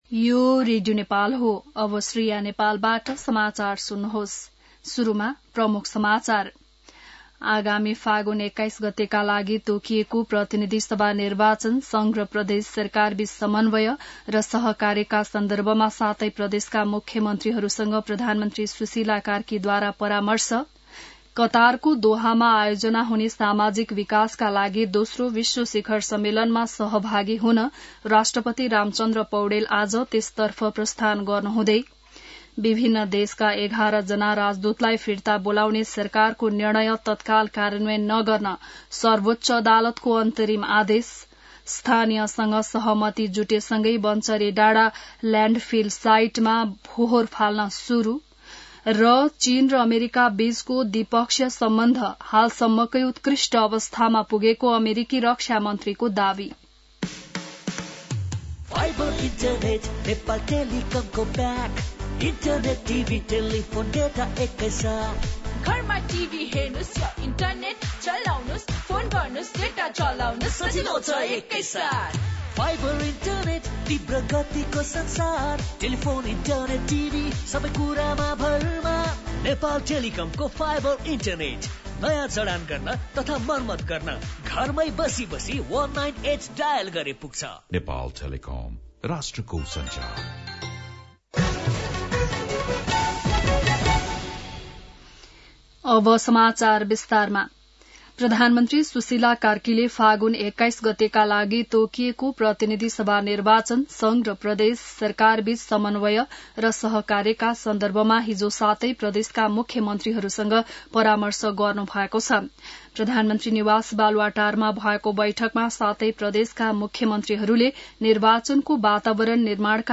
An online outlet of Nepal's national radio broadcaster
बिहान ७ बजेको नेपाली समाचार : १७ कार्तिक , २०८२